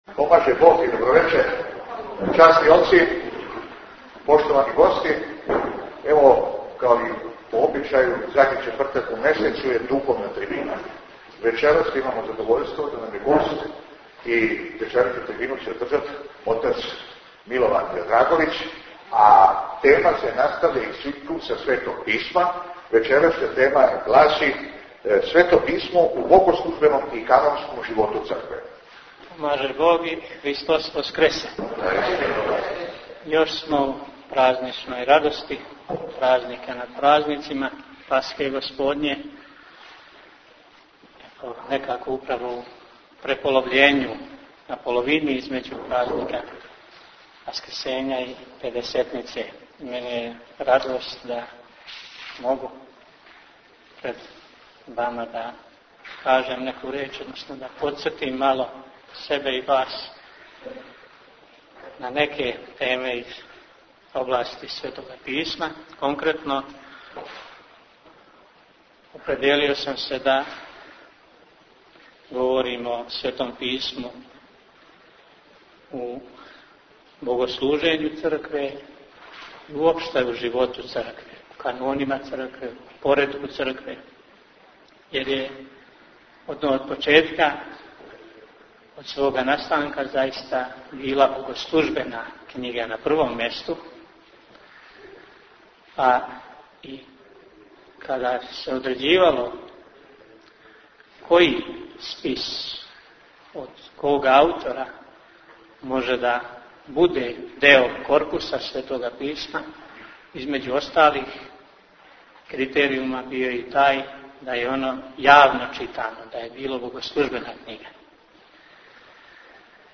Звучни запис предавања
Суботица